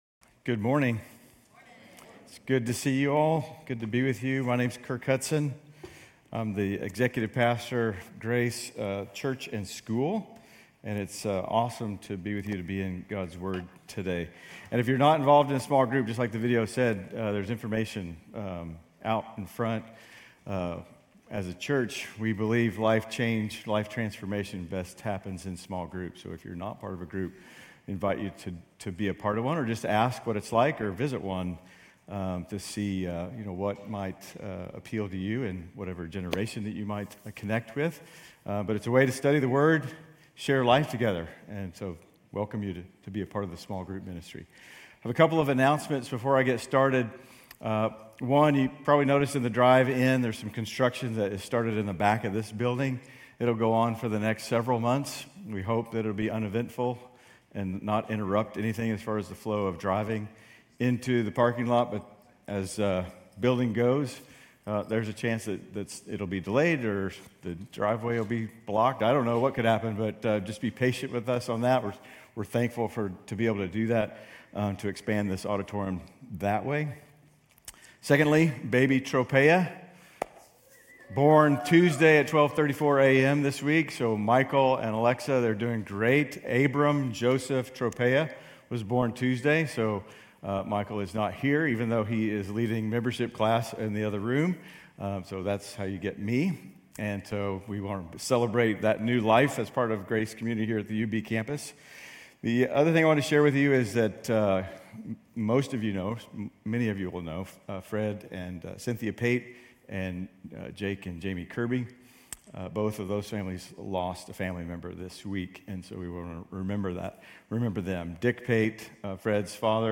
Grace Community Church University Blvd Campus Sermons Gen 17:15-27 - Isaac Oct 21 2024 | 00:28:29 1x 00:00 / 00:28:29 Subscribe Share RSS Feed Share Link Embed